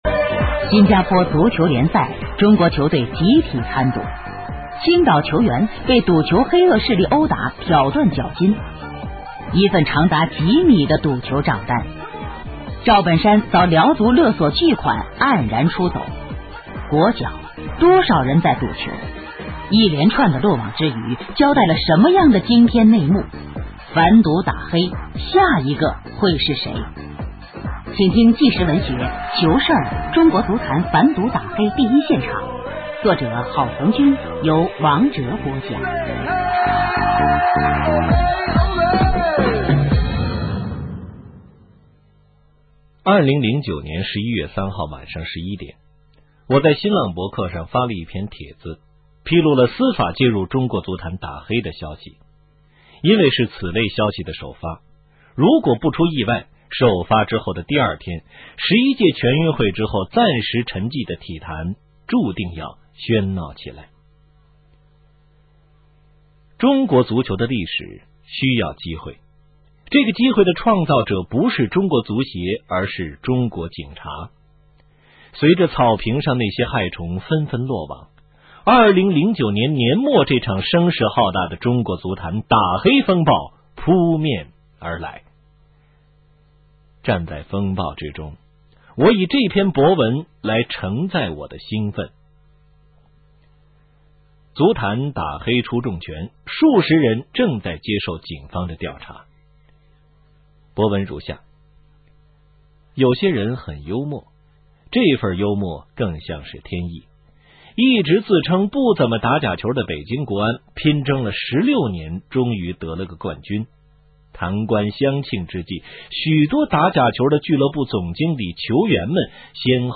【有声文学】《球事儿中国足坛反赌打黑第一现场》